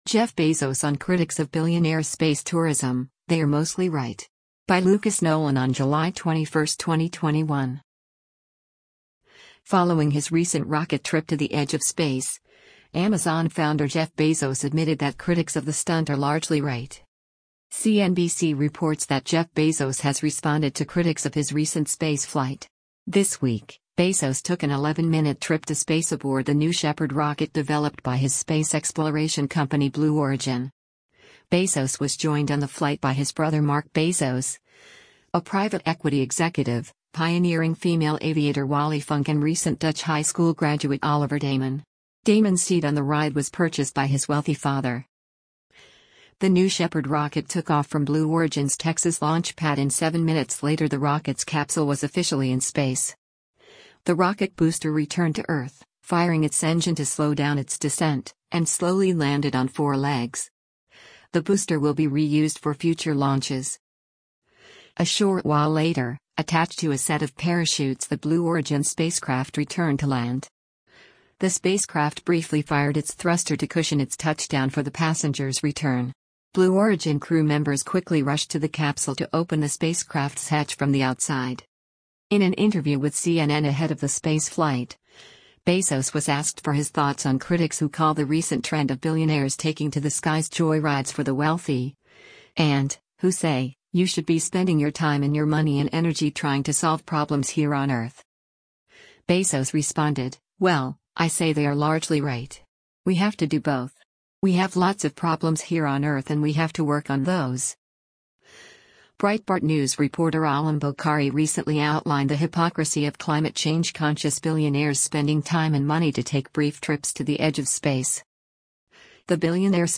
Jeff Bezos speaks about his flight on Blue Origin’s New Shepard into space during a pres